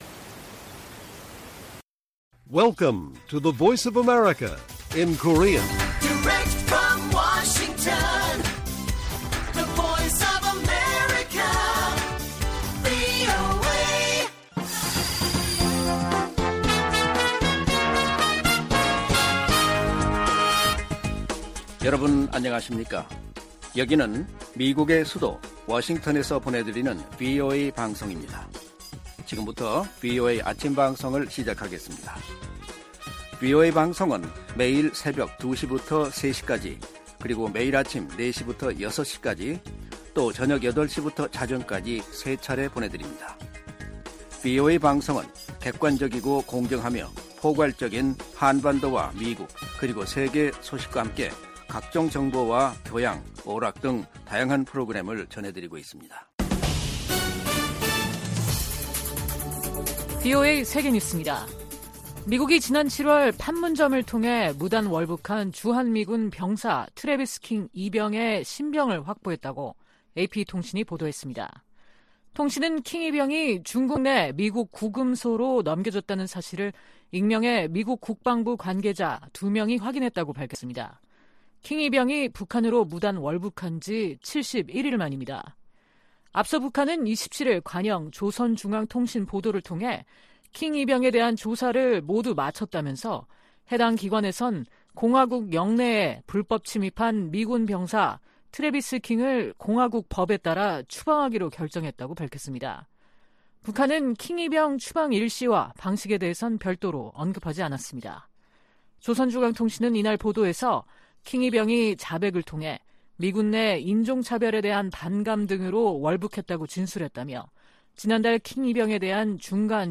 세계 뉴스와 함께 미국의 모든 것을 소개하는 '생방송 여기는 워싱턴입니다', 2023년 9월 28일 아침 방송입니다. '지구촌 오늘'에서는 미국 정부가 위구르족 강제노동 등 사유로 중국 기업 3곳을 제재 대상에 추가한 소식 전해드리고, '아메리카 나우'에서는 조 바이든 대통령이 자동차노조 파업 현장을 지지 방문한 이야기 살펴보겠습니다.